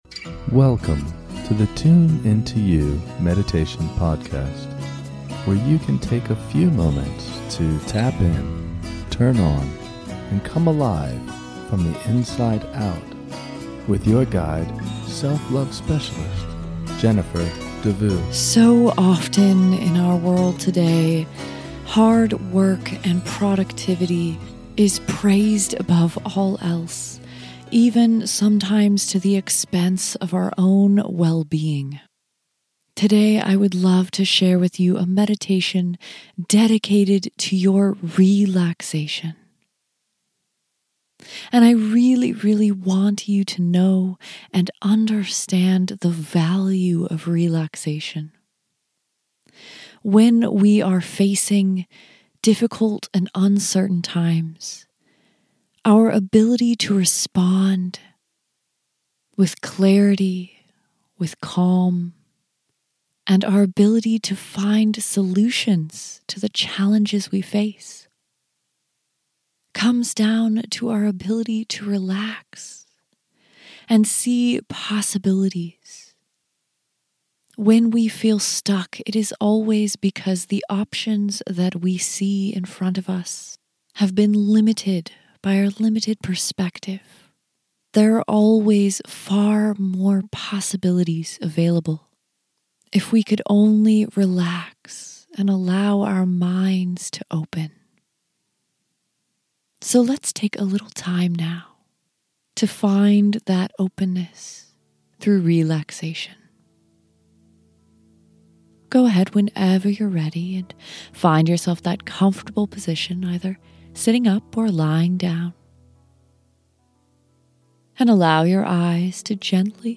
10 min Relaxation Meditation
In this short guided meditation, we will release our worries with a simple visualization and breath. Let the ocean waves wash away your worries.